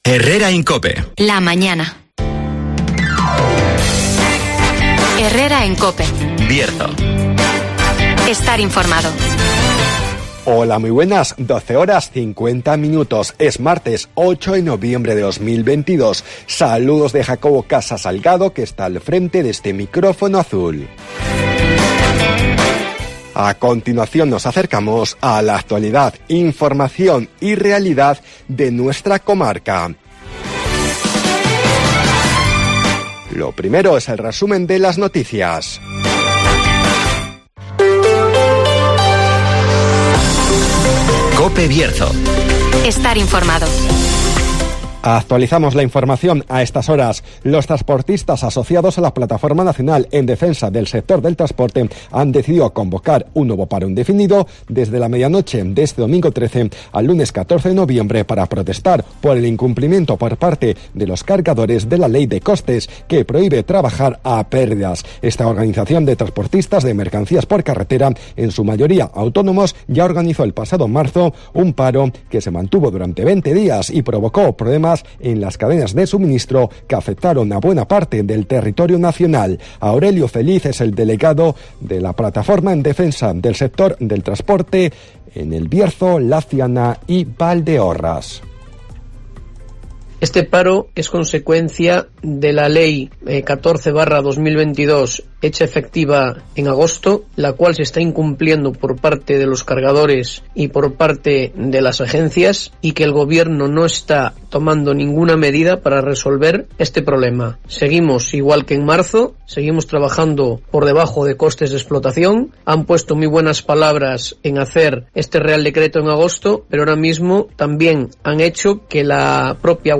Este sábado, Marcha Solidaria de la Asociación de Diabéticos del Bierzo -Adebi- (Entrevista